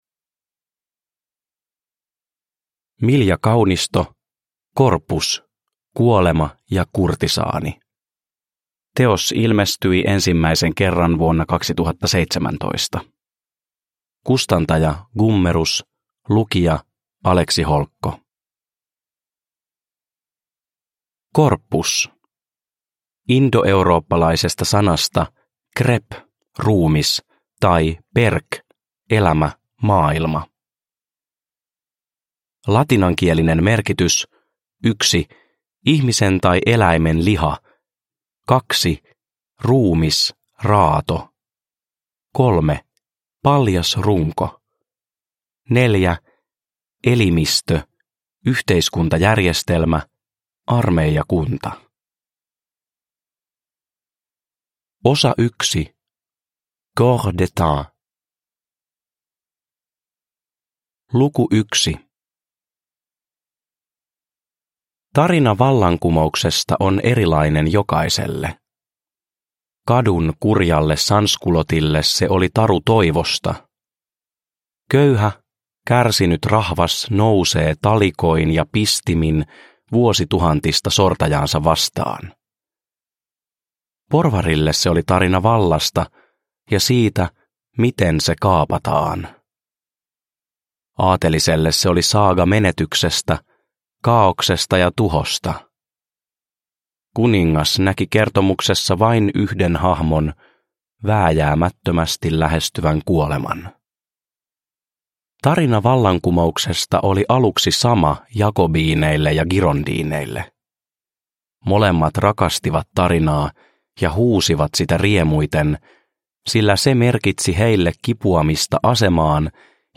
Corpus – Ljudbok – Laddas ner